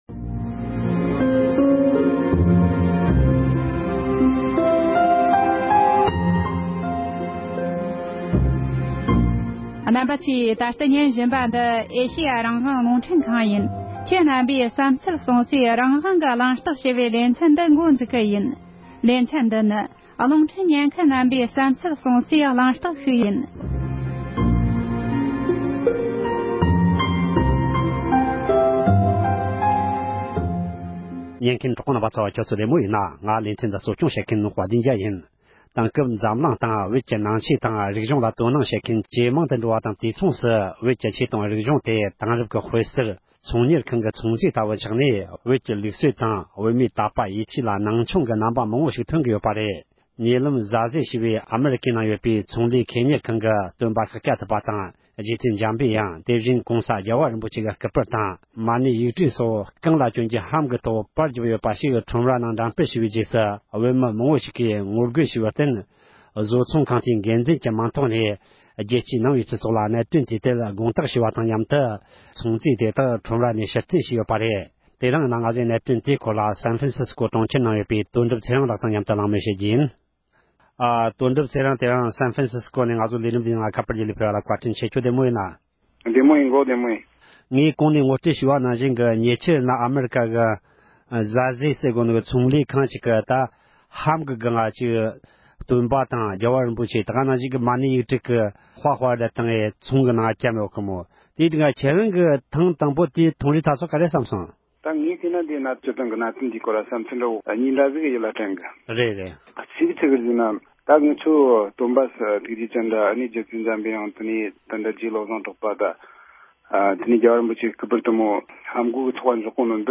ཛ་ཛལ་ཞེས་པའི་བཟོ་ཁང་གིས་སྟོན་པའི་སྐུ་པར་དང་༸གོང་ས་མཆོག་གི་སྐུ་པར་སོགས་ལྷན་གྱི་སྟེང་པར་བསྐྲུན་བྱས་པའི་སྐོར་གླེང་མོལ།